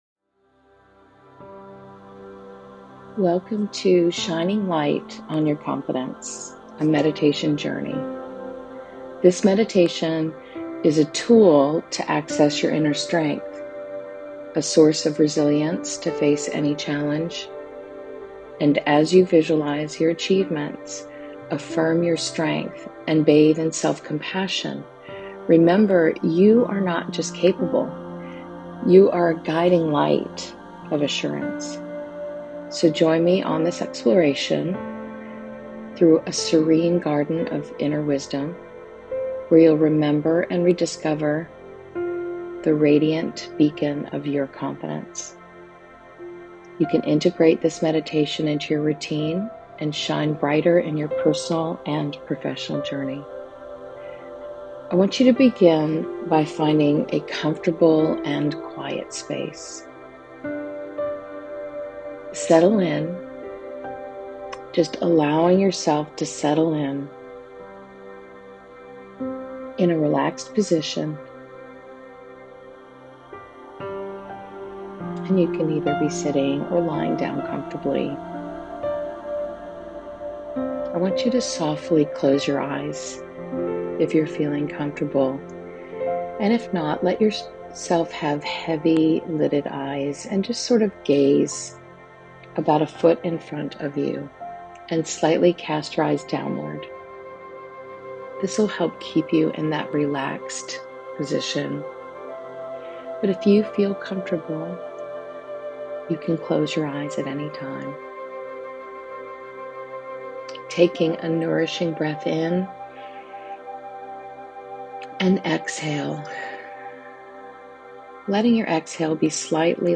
This empowering meditation invites you to step into your light, embracing confidence and strength as guiding forces on your journey.